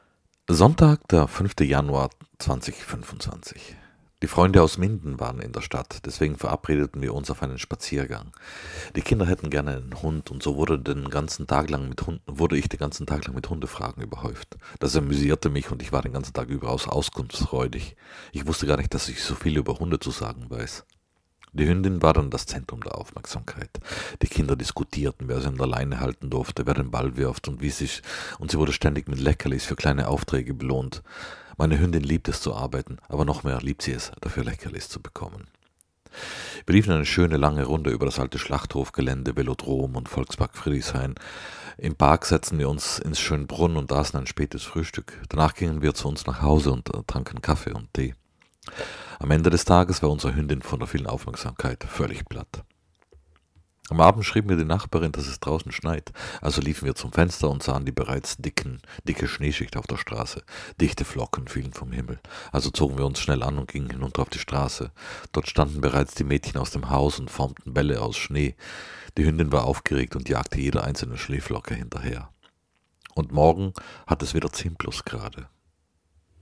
Schnee: